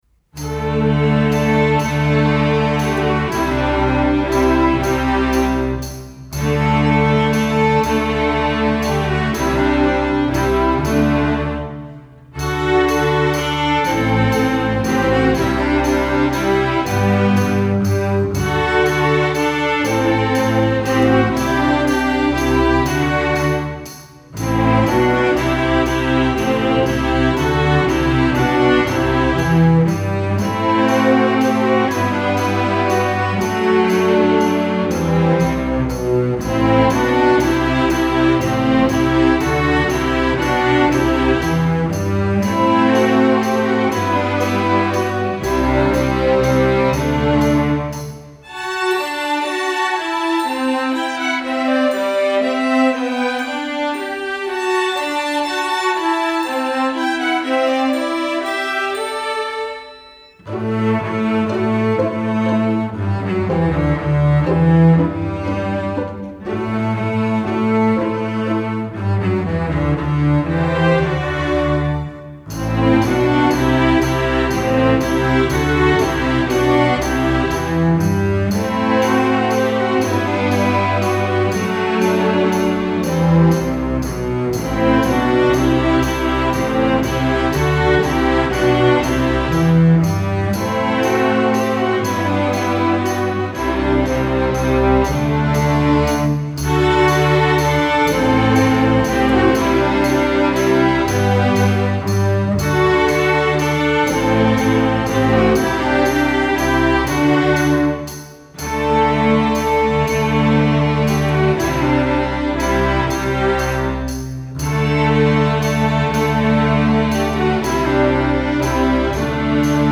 Instrumentation: string orchestra
masterwork arrangement
1st percussion part: